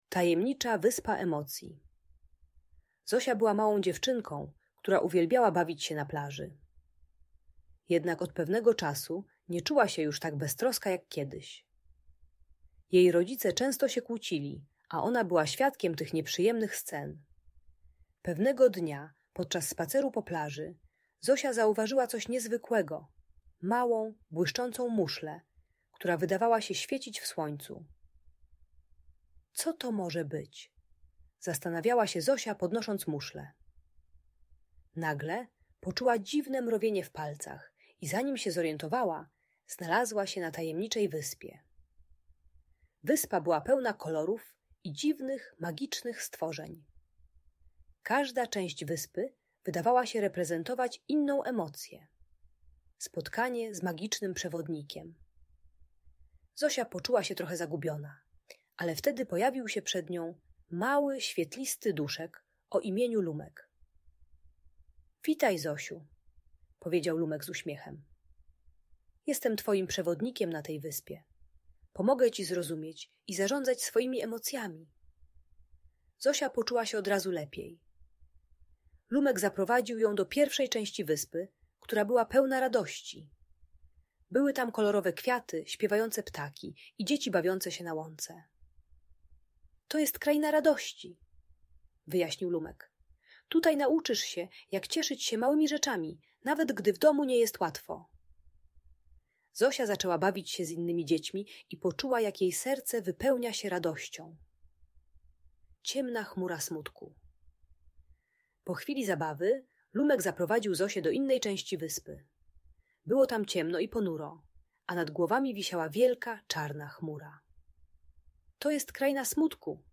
Tajemnicza Wyspa Emocji - Audiobajka